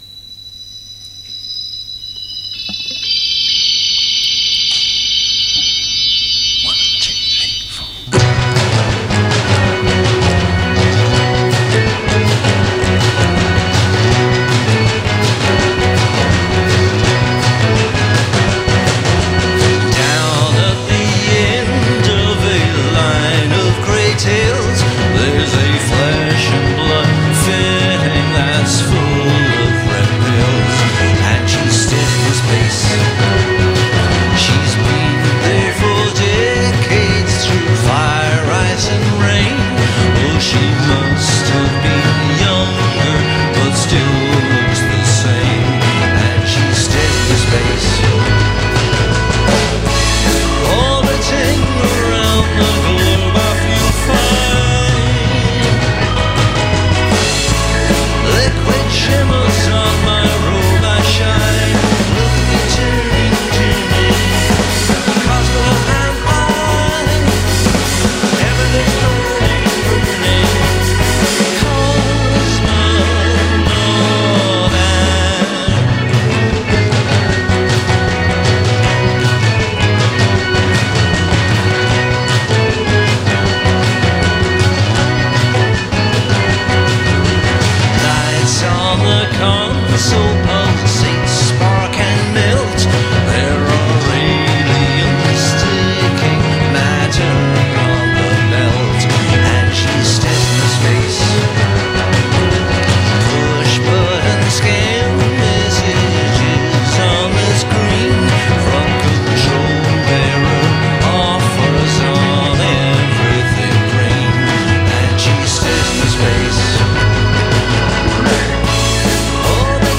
and recorded live from Maida Vale Studios on September 238
Post-Punk is alive and well.